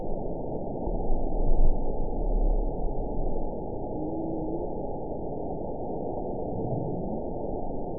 event 910488 date 01/21/22 time 23:10:12 GMT (3 years, 4 months ago) score 8.98 location TSS-AB04 detected by nrw target species NRW annotations +NRW Spectrogram: Frequency (kHz) vs. Time (s) audio not available .wav